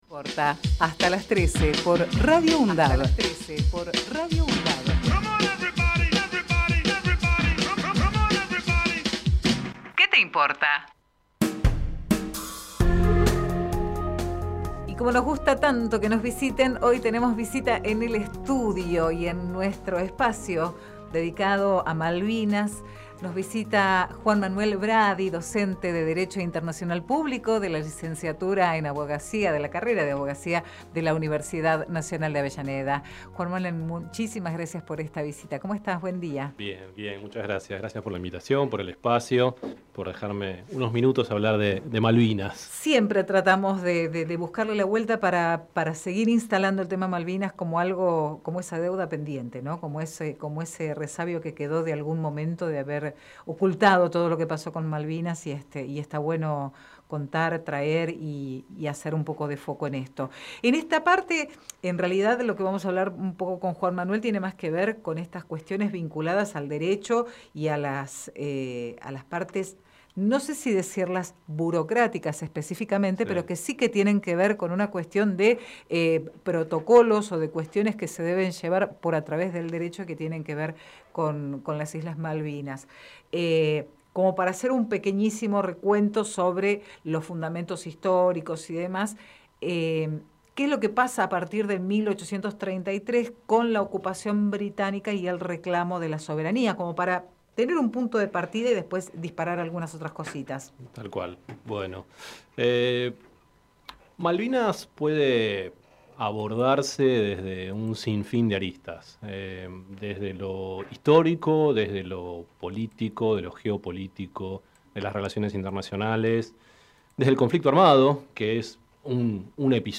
COLUMNA MALVINAS Entrevista